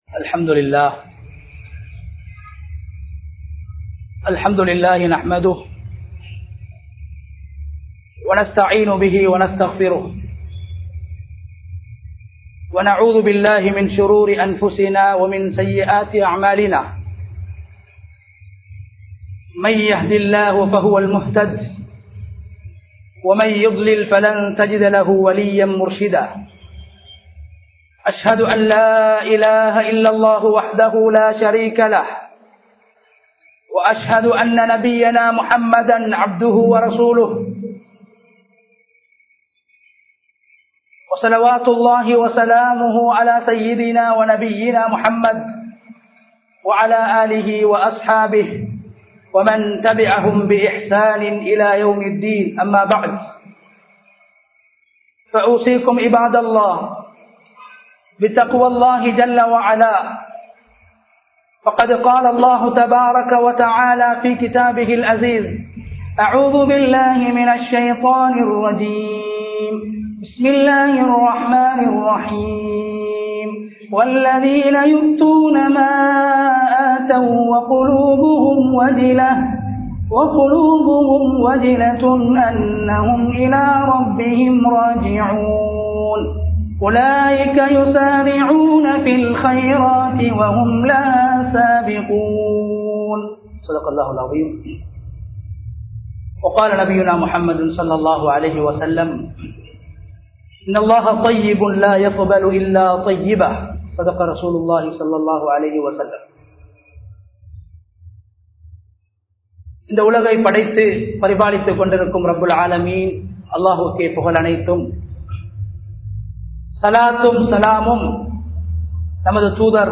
ஏற்றுக்கொள்ளப்பட்ட நல்அமல்கள் | Audio Bayans | All Ceylon Muslim Youth Community | Addalaichenai
Colombo 15, Mattakkuliya, Kandauda Jumua Masjidh 2022-04-29 Tamil Download